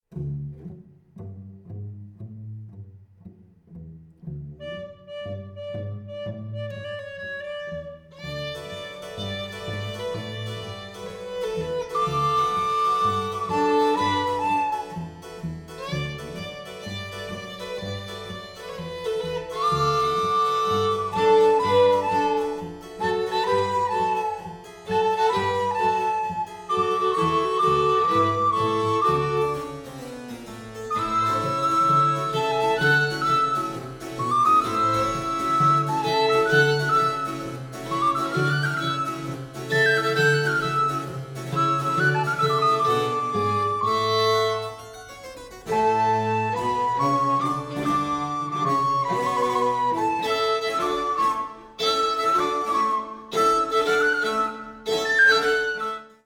recorders
violin
cello
harpsichord